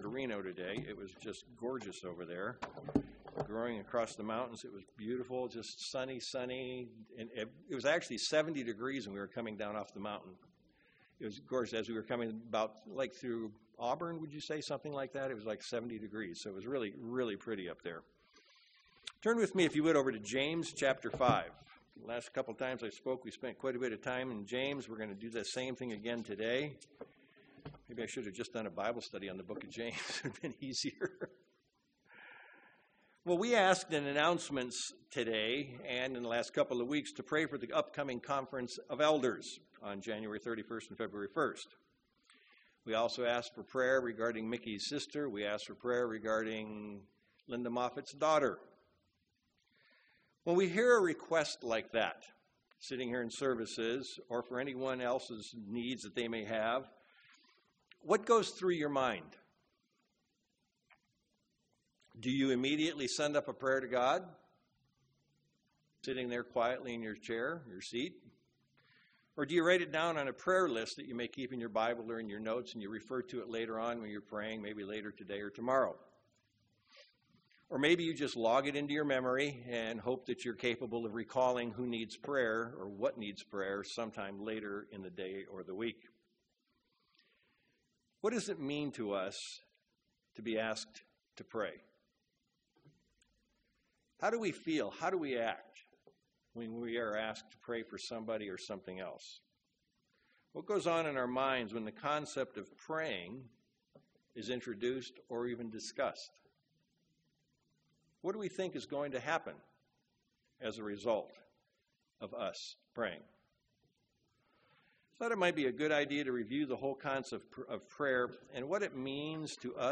Given in Sacramento, CA
UCG Sermon Studying the bible?